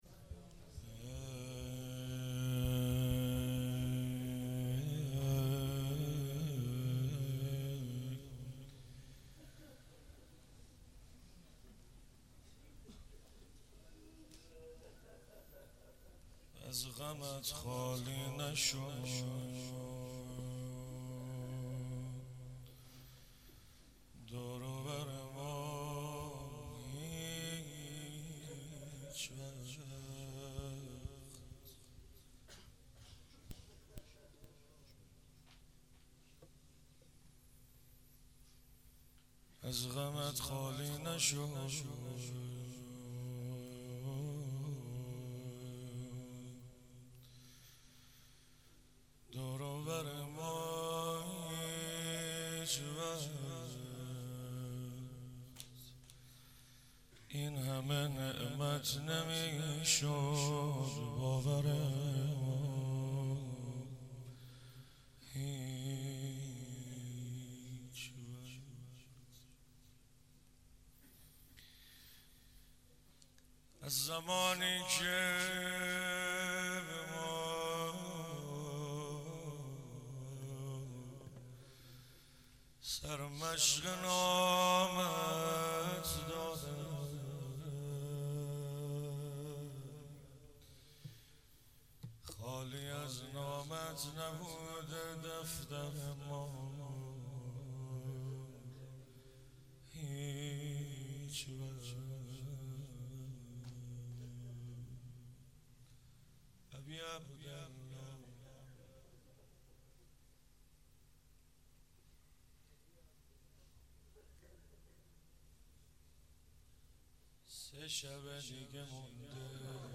مراسم شب هشتم محرم الحرام 94 :: هیئت علمدار